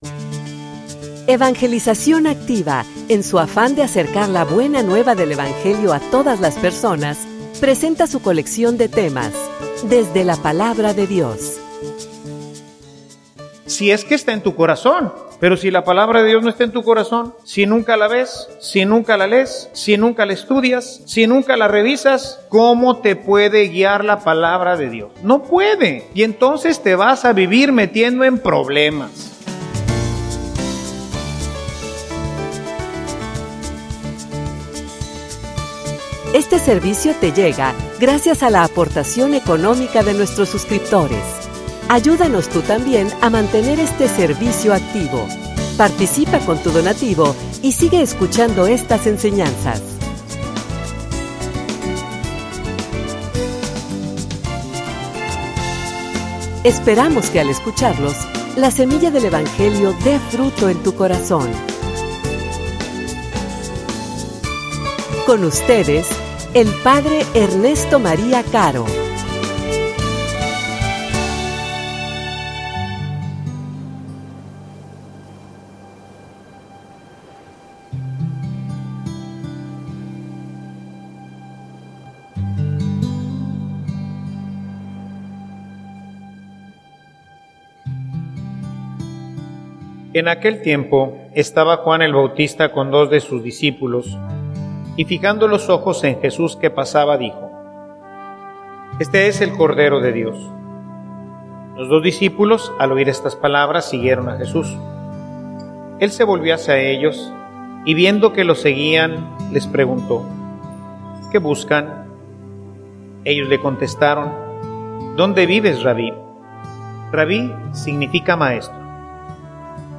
homilia_Lampara_para_mi_camino.mp3